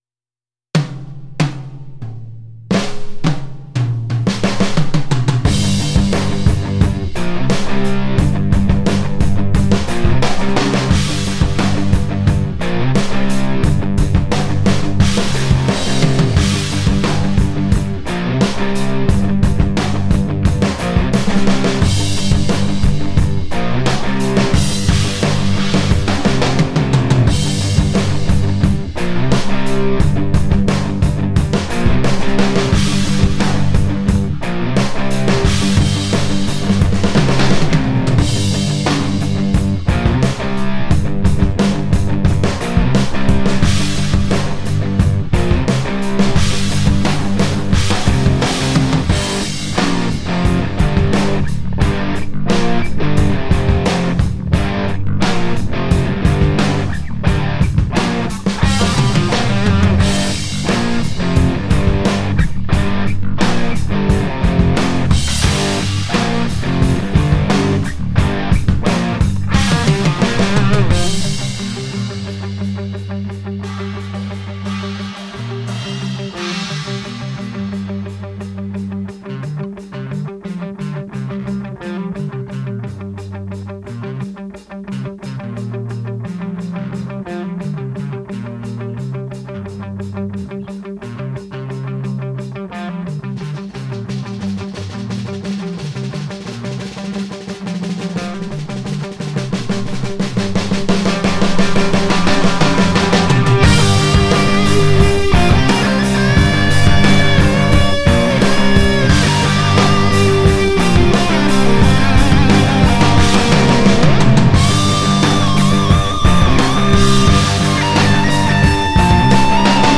BFD Vintage Recording Techniques 是 BFD3 的经典复古鼓组扩展，核心是用 5 种传奇录音技法 完整收录一套 Ludwig Classic Maple 枫木鼓，主打 60–80 年代摇滚、流行、爵士、布鲁斯的温暖复古音色。
• 鼓组原型Ludwig Classic Maple（经典枫木套鼓，温暖通透、共鸣饱满）
• 镲片：Zildjian A 系列（复古亮泽、自然衰减）
• 29 个独立麦克风通道（单鼓多麦 + 5 套全景 overhead/room）
• 风格：复古摇滚、流行、爵士、布鲁斯、放克、乡村